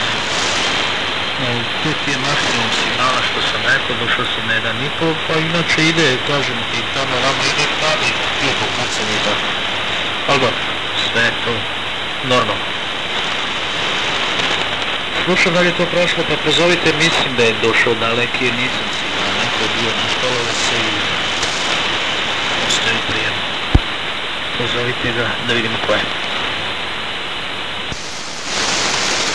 Pirate radio from Serbia received in Finland
Serbian pirates on Medium Wave
They are mostly QSO-stations with no music programmes.